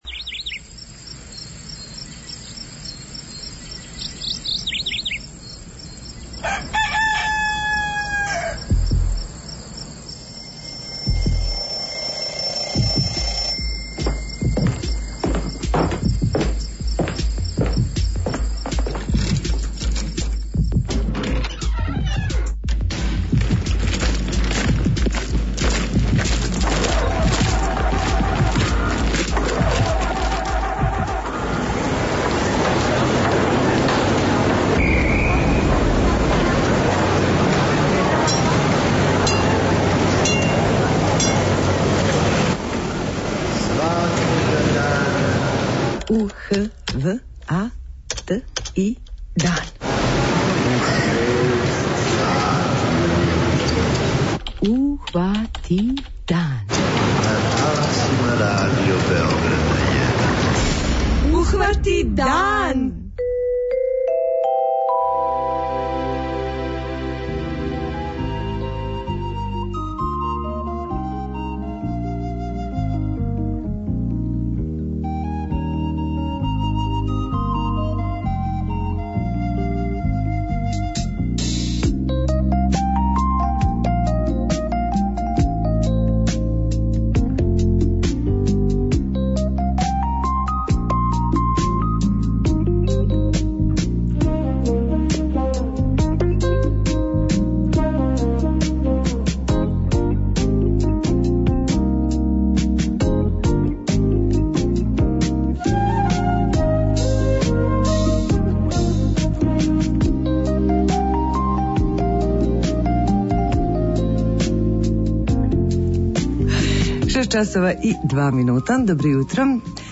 Као и увек петком, на крају програма очекује Вас наш наградни квиз.